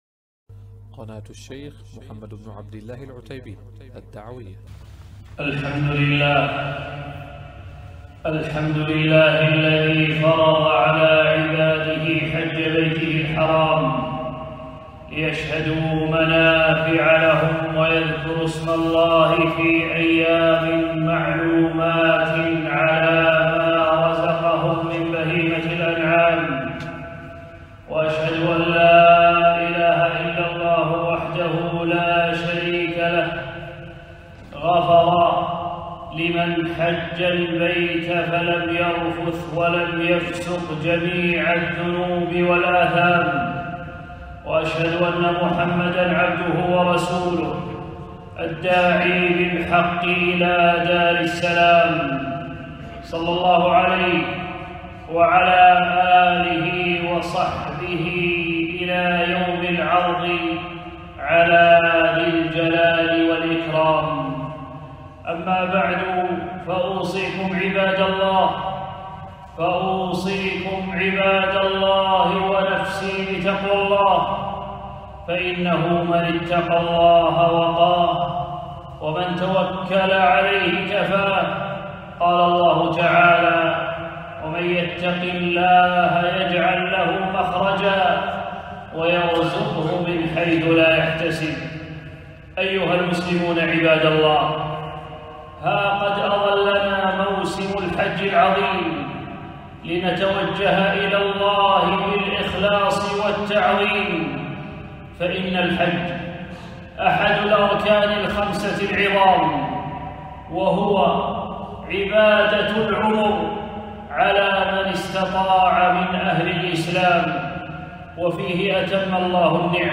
خطبة - وأذن في الناس بالحج